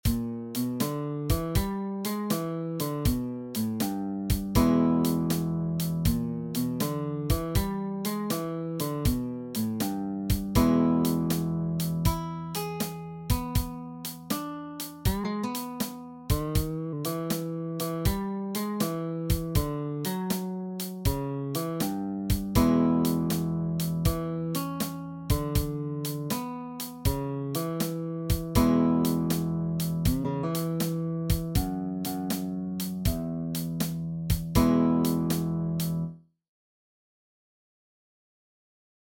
Complicated ist ein 12-Takter, der nicht dem typischen Bluesschema folgt. Dieser Blues in E-Dur wird bevorzugt mit den Fingern auf einer akustischen Gitarre gespielt.
Die Akzente werden durch Bending und Vibrato gesetzt, aber auch durch Pull-off, Hammer-on und den Slide.